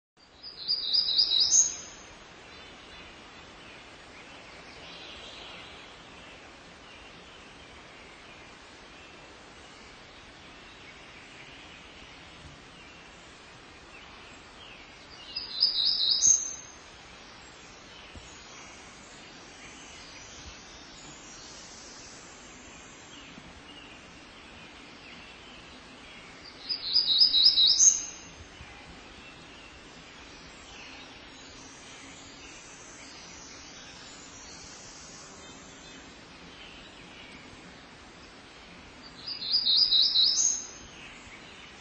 Black-throated Gray Warbler
Dendroica nigrescens
Bird Sound
Song a series of buzzy notes, full of z's, "zeedle zeedle zeedle zeet-chee."
Black-throatedGrayWarbler .mp3